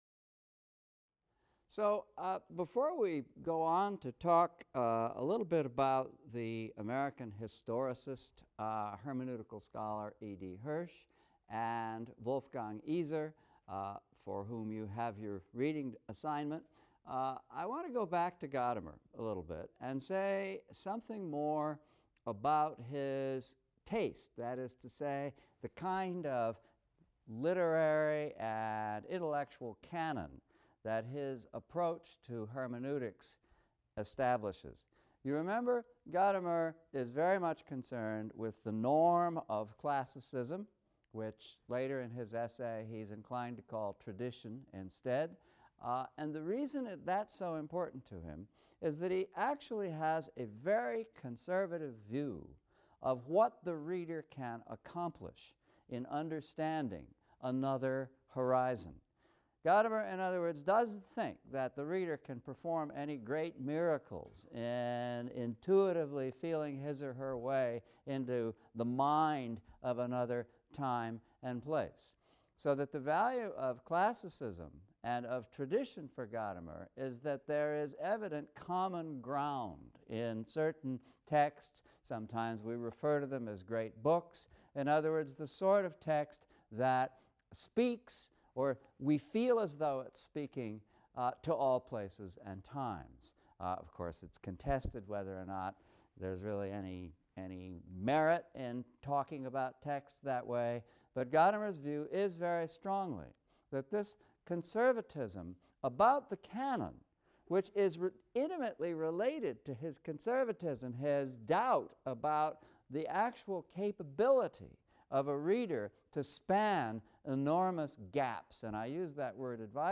ENGL 300 - Lecture 4 - Configurative Reading | Open Yale Courses